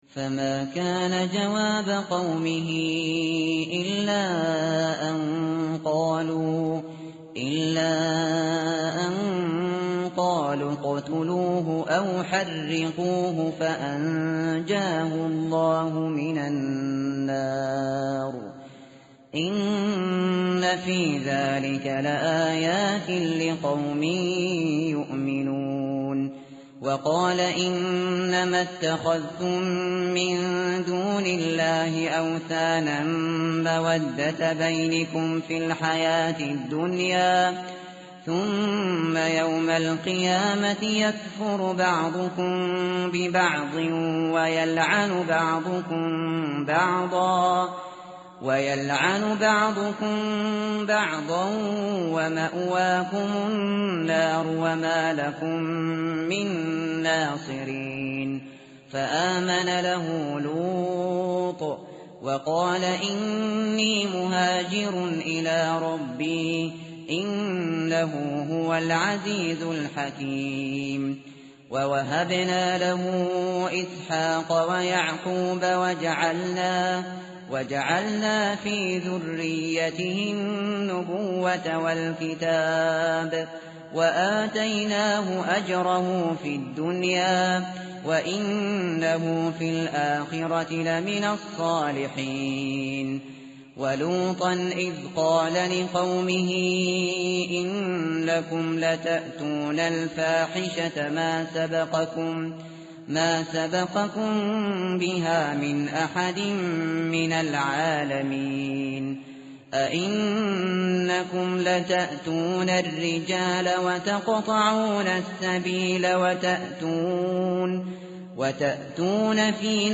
tartil_shateri_page_399.mp3